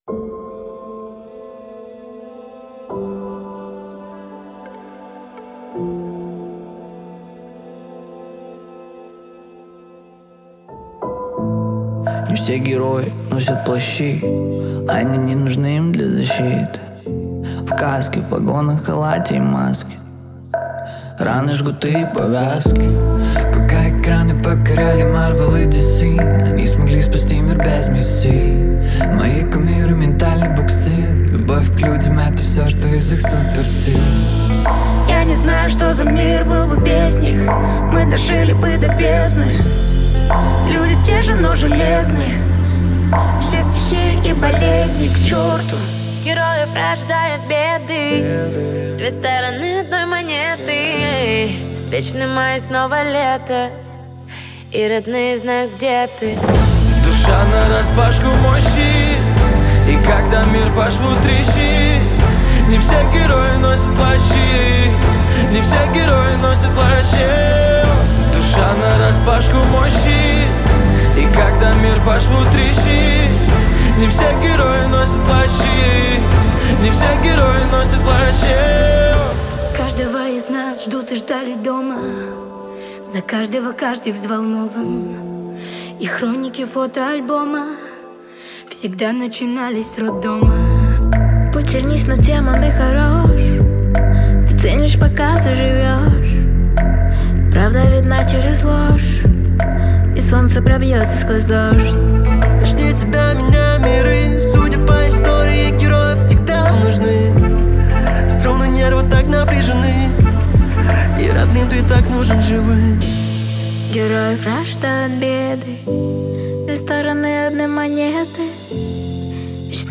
это мощный трек в жанре поп